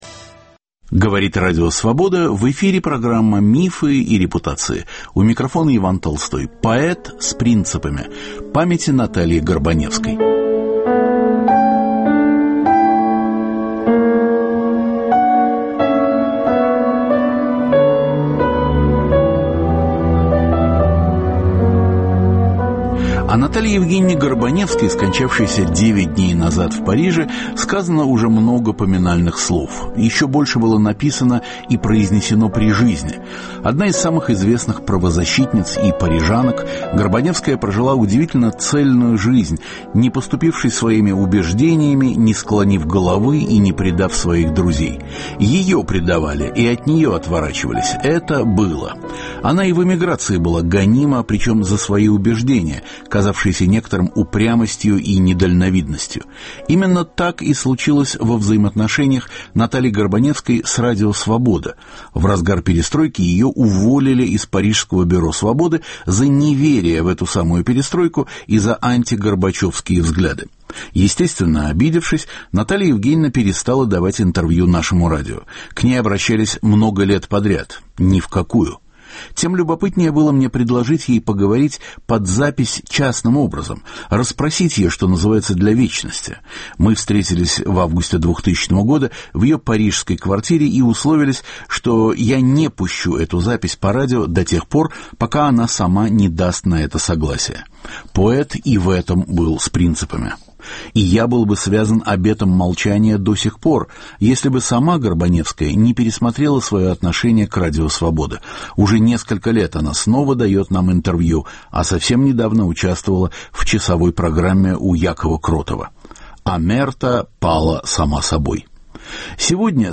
Беседа с правозащитницей и многолетней внештатницей Радио Свобода записана в 2000 году. Разговор идет о годах эмиграции, о радиопередачах, о поддержке советских диссидентов западной общественностью. Наталья Горбаневская читает свои стихи.